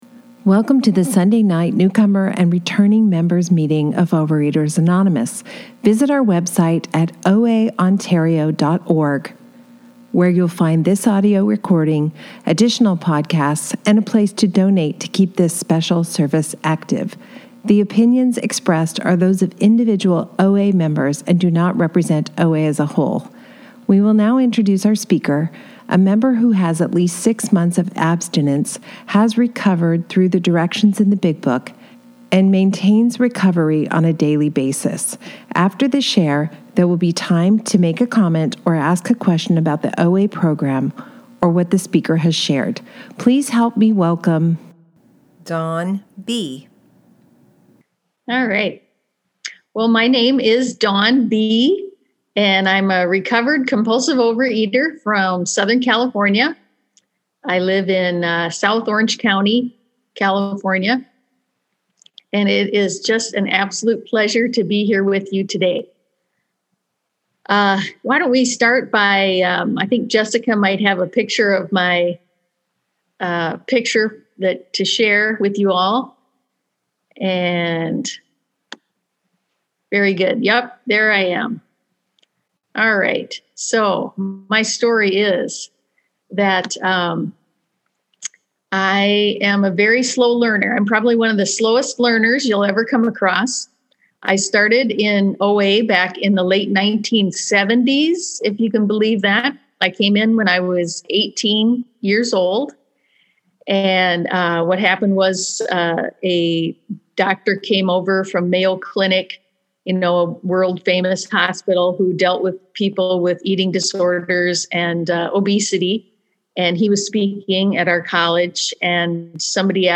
OA Newcomer Meeting